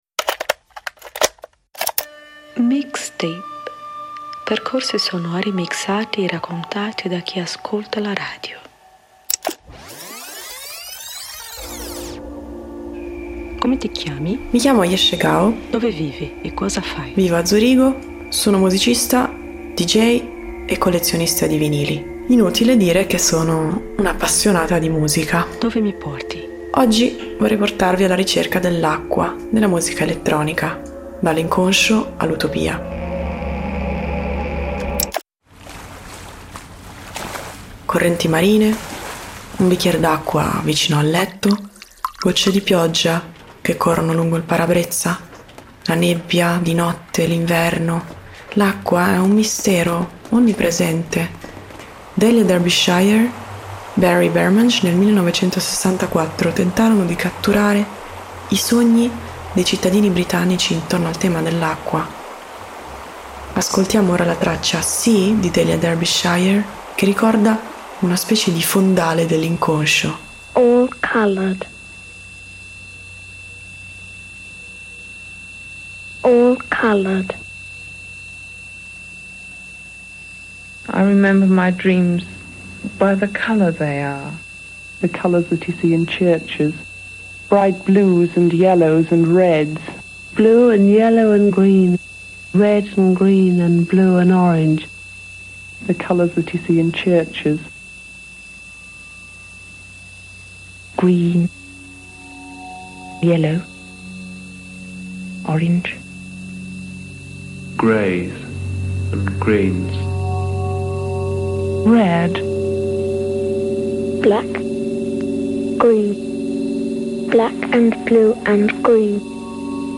È un accostamento ardito ma - forse proprio per questo - affascinante e fertile: acqua e musica elettronica.